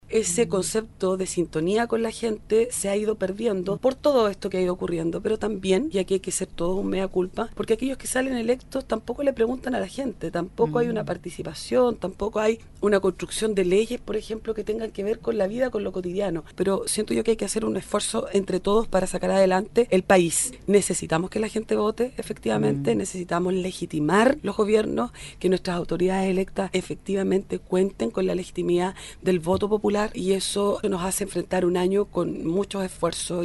Uno de los temas que la Gobernadora Provincial de Osorno, María Tabita Gutiérrez destacó como avance en el año 2016 está concentrado en el ámbito educación, con la consolidación de la reforma que ha ido avanzando en el tiempo, especialmente en materia de gratuidad en los establecimientos educativos, como también, lo que se proyecta aplique en la educación técnico profesional. En conversación con radio Sago, la autoridad provincial explicó que esa es una de las principales tareas de nivel del Gobierno y que según lo programado podrá concretarse además, con la existencia en la región  de un instituto profesional gratuito.